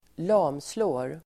Uttal: [²l'am:slå:r]